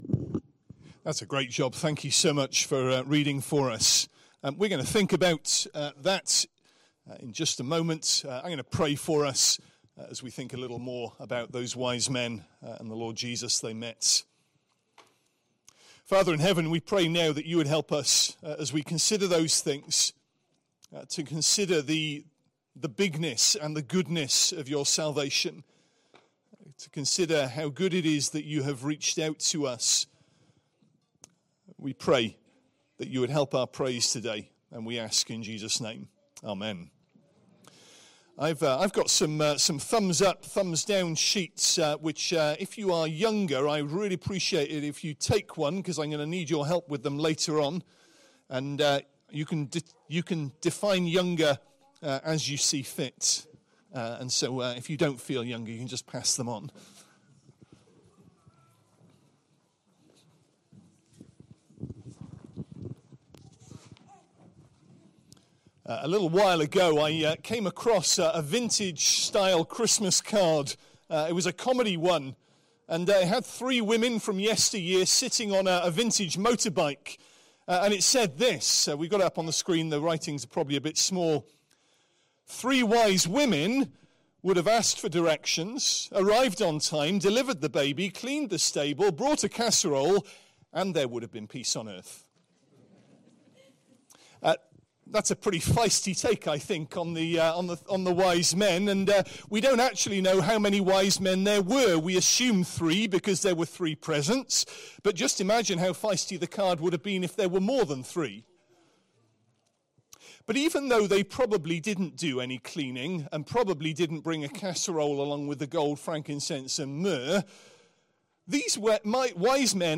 Christ Church Sermon Archive
Sunday AM Service Thursday 25th December 2025 Speaker